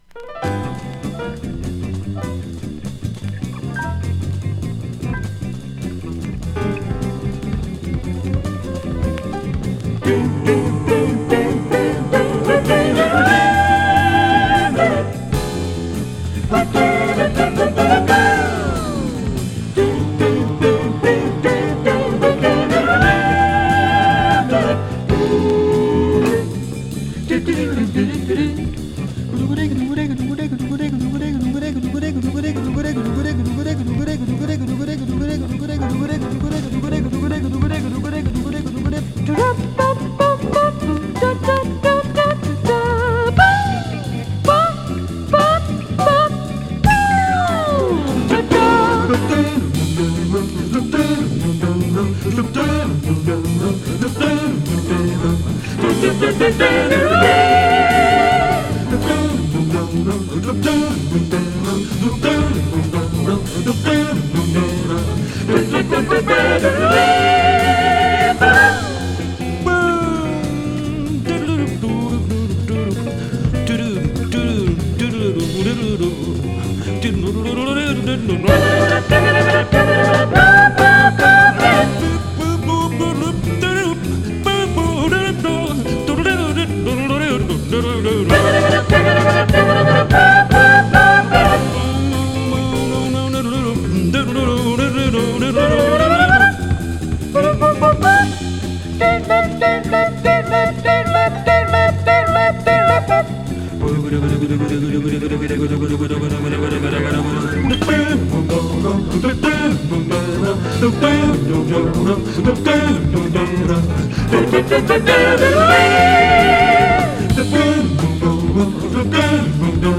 ポーランドのコーラス・グループ
高速スキャット・ジャズ